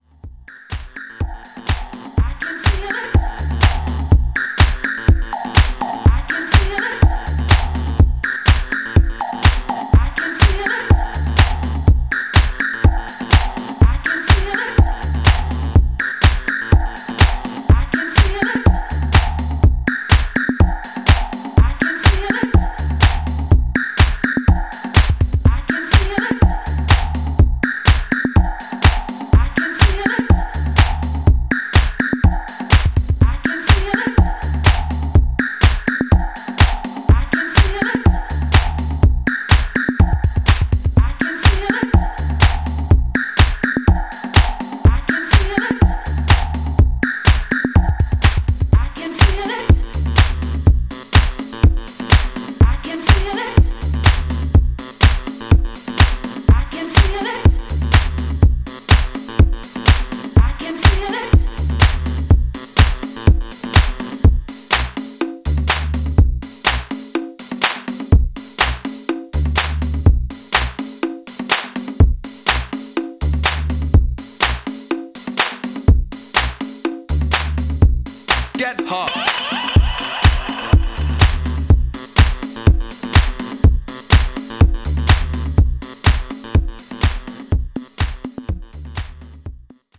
ラフでミニマルなディスコサンプルと楽観的で時に調子外れのメロディー、もっさりと足取りを奪うグルーヴ。
何はともあれディスコ/ハウス史に残る、キュートで親しみやすくクレイジーな金字塔です。